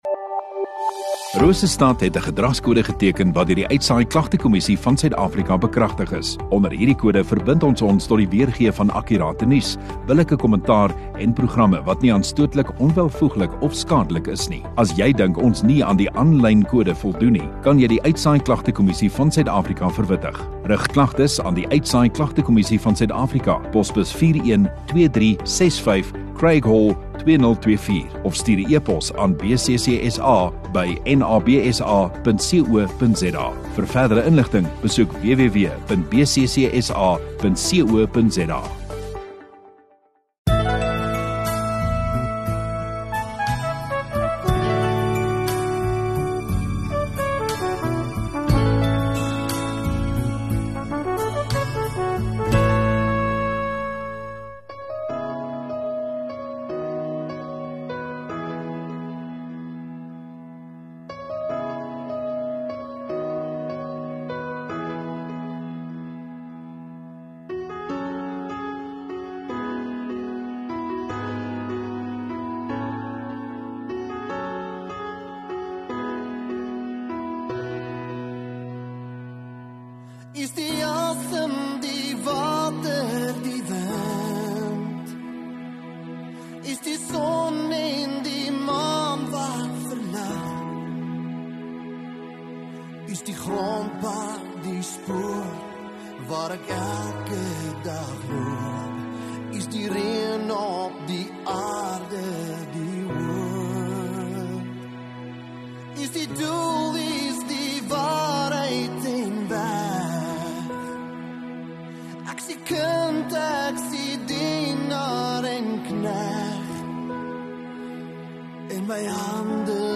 16 Mar Saterdag Oggenddiens